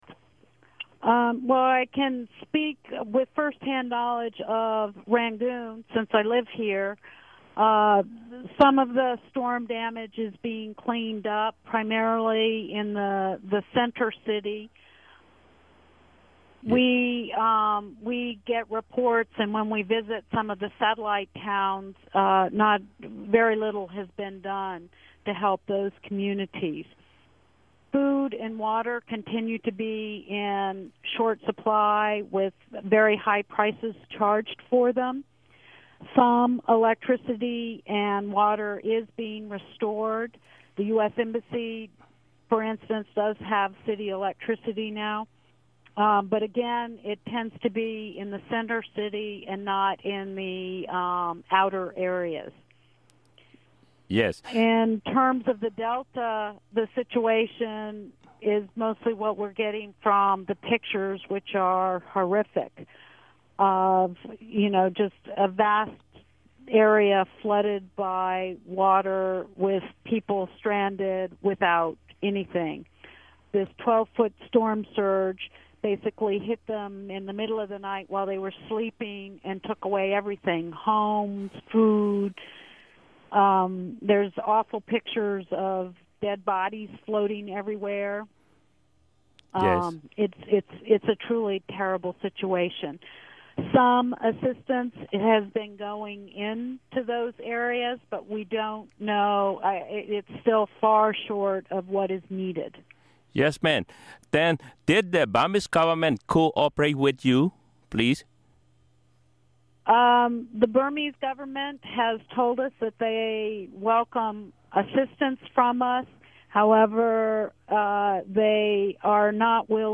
Shari Villarosa Interview